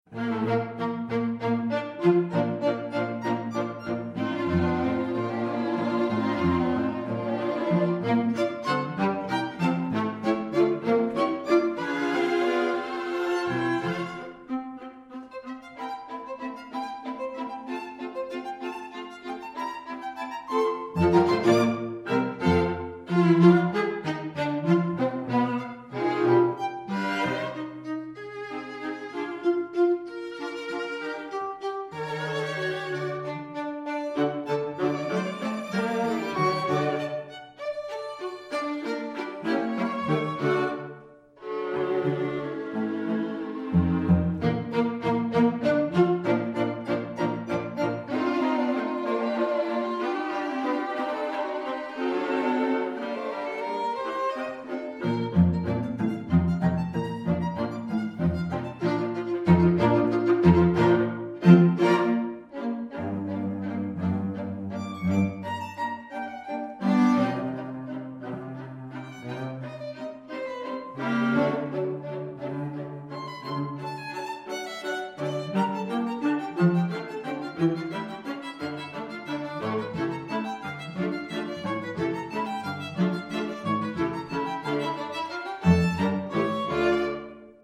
For 2 Violins, 2 Violas and Violoncello